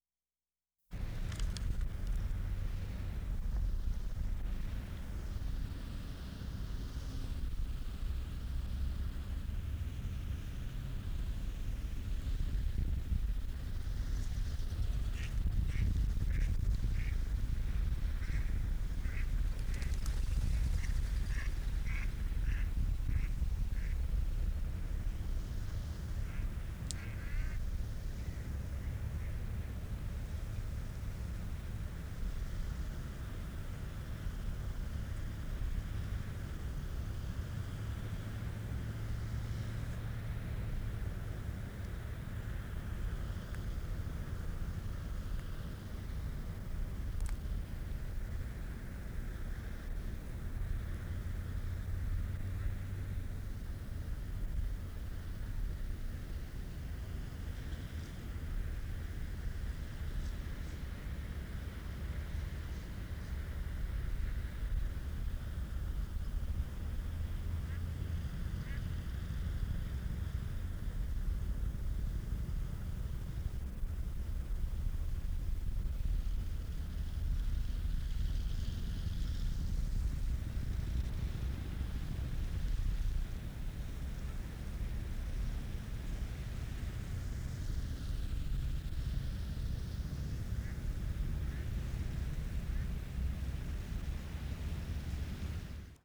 SOME DOWNTOWN NOISE "GROVES" March 19, 1973
1/2. Mostly traffic and wind noise, one duck squawks in solitary splendor.
The lagoon is lost in the noise.